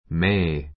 may 小 A1 弱形 mei メイ 強形 méi メ イ 意味map 助動詞 ❶ ～してもよい ❷ ～かもしれない 助動詞 過去形 might 弱形 mait マイ ト 強形 máit マ イ ト ❶ ～してもよい ⦣ 話し言葉では can のほうが好まれる. can 1 ❷ You may go.